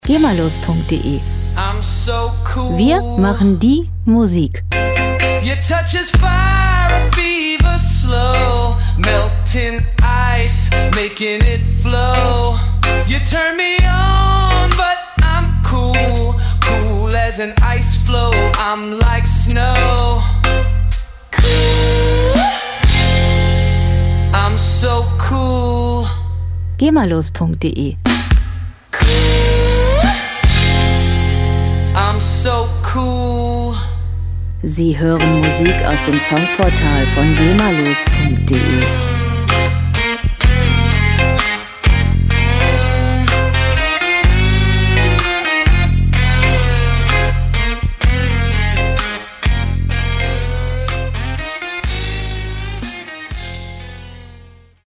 Lounge Musik - Cool und lässig
Musikstil: Blues Rock
Tempo: 63 bpm
Tonart: F-Moll
Charakter: bluesig, lässig
Instrumentierung: Rocksänger, E-Bass, E-Gitarre, Drums